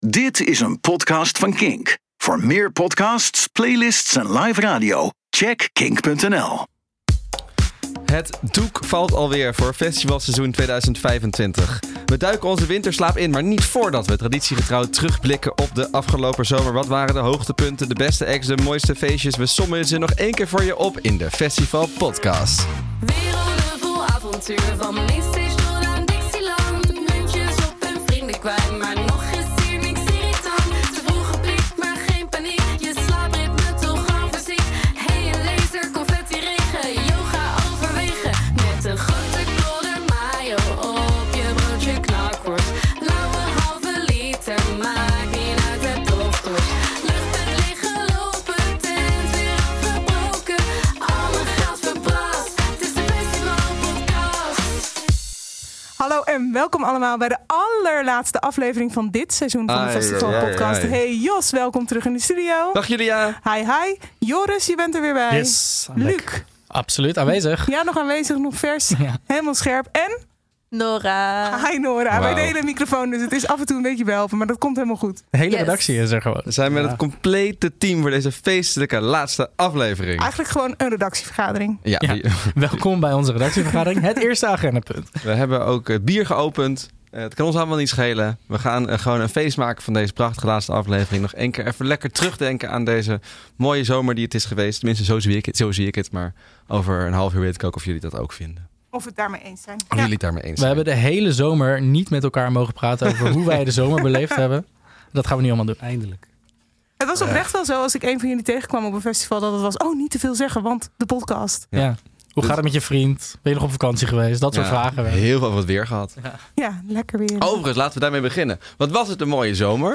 De hele podcastredactie is bij elkaar om alles nog sommen ze nog één keer voor je op te sommen in ouderwetse kletspot-vorm!